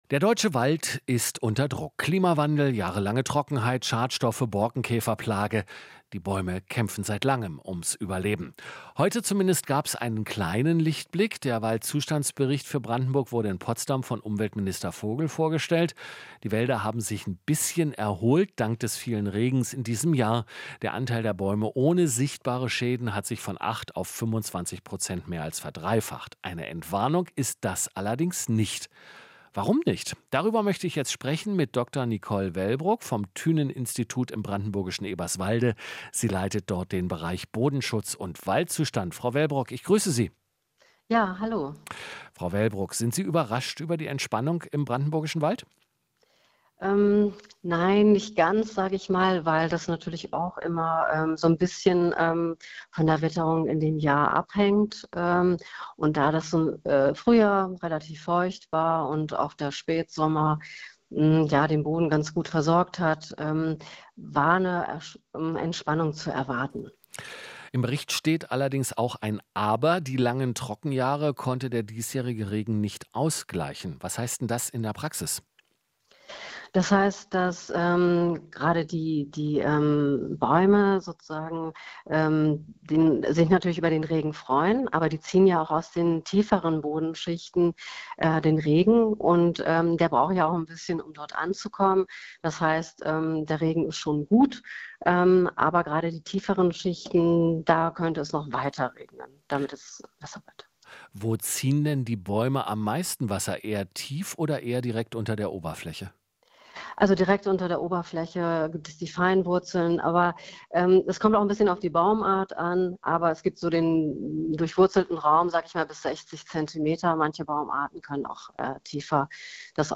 Interview - Waldzustandsbericht: Leichte Erholung in Brandenburg